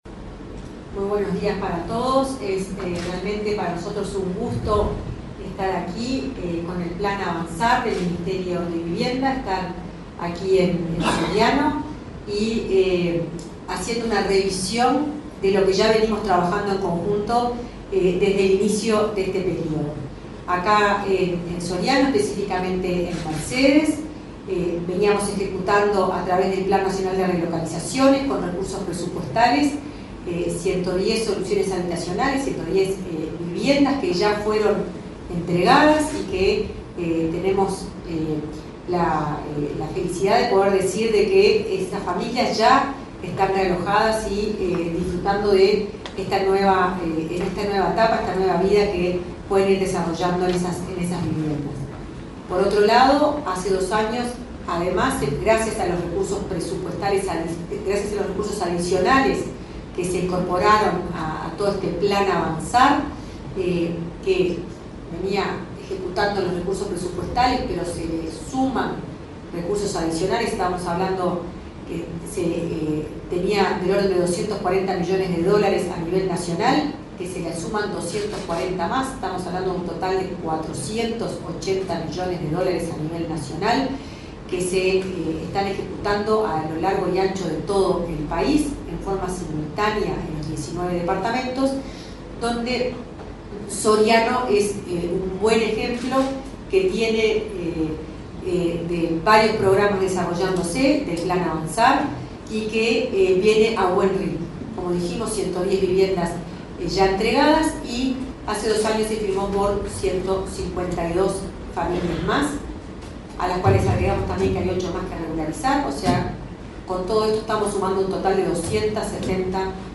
Palabras de autoridades en la Intendencia de Soriano
Palabras de autoridades en la Intendencia de Soriano 20/08/2024 Compartir Facebook X Copiar enlace WhatsApp LinkedIn La directora de Integración Social y Urbana del Ministerio de Vivienda y Ordenamiento Territorial, Florencia Arbeleche, y el intendente de Soriano, Guillermo Besozzi, brindaron una conferencia de prensa, para informar acerca de la revisión de los acuerdos estratégicos del plan Avanzar en ese departamento.